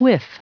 Prononciation du mot whiff en anglais (fichier audio)
whiff.wav